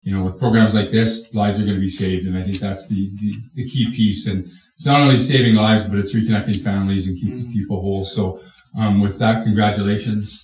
Renfrew Mayor Tom Sidney was among the dignitaries who spoke. He said Renfrew and Renfrew County are synonomous with creativity and thinking outside and credited the team for being leaders in their field.